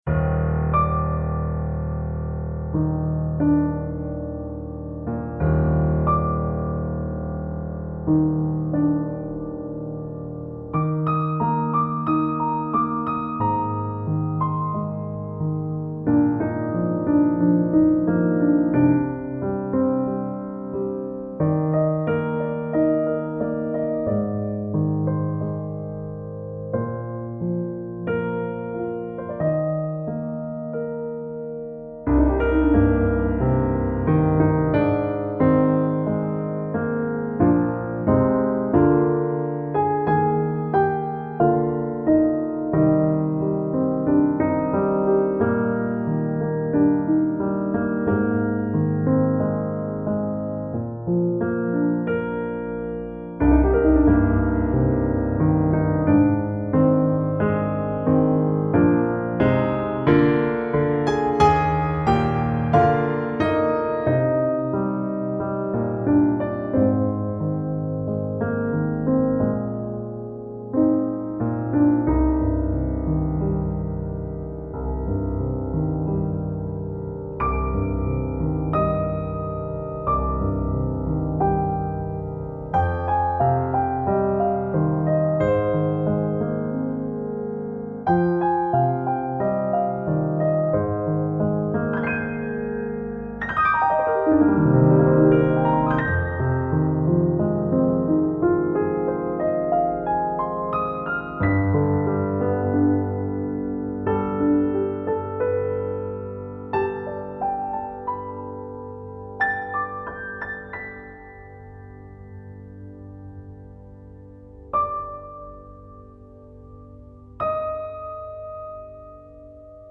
主要演奏器乐： 钢琴
乐风：新世纪、疗癒